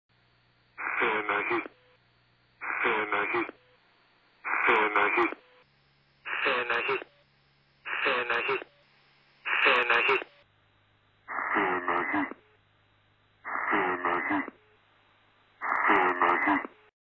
I have it repeated 9 times. 3 times at normal speed, 3 at 115% speed and 3 at 85% speed. The last run at each speed has the upper and lower frequencies taken down, so that the mid-range is clearer... the voice range.